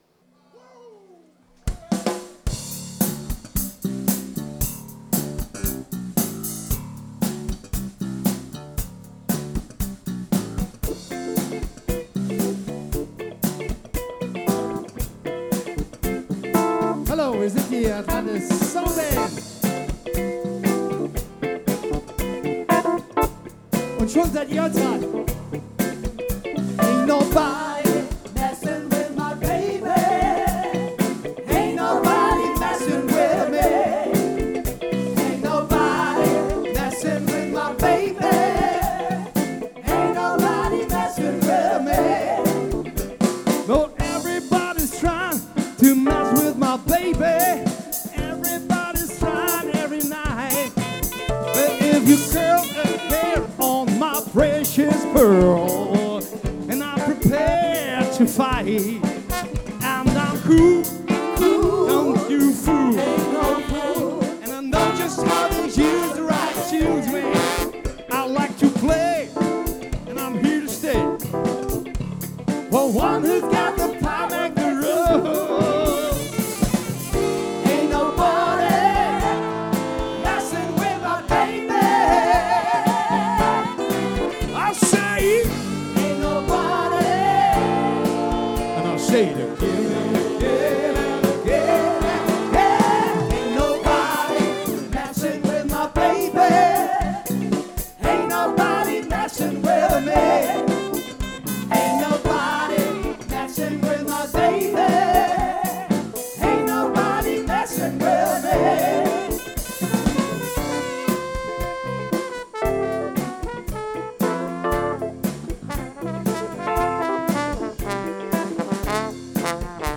· Genre (Stil): Soul
· Kanal-Modus: mono · Kommentar